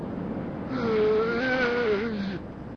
mgroan7.ogg